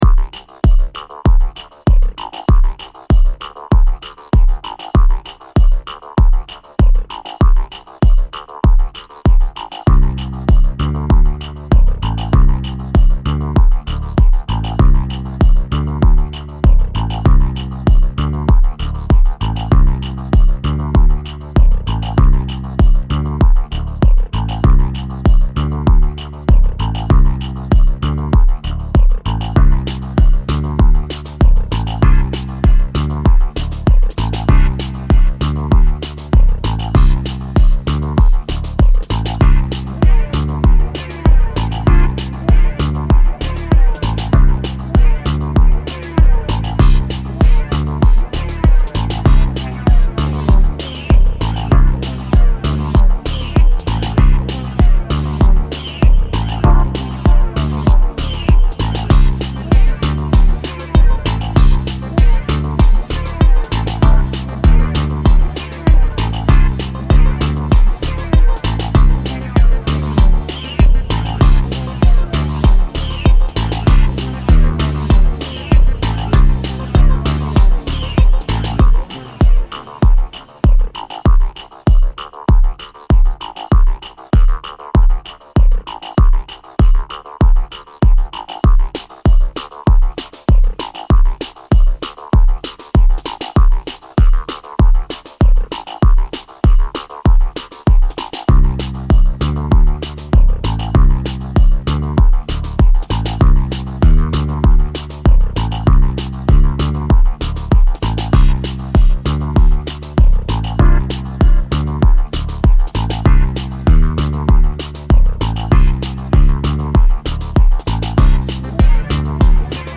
I used ld auricula as newer generator. bmw rules!!
Genre Electronica